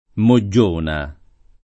[ mo JJ1 na ]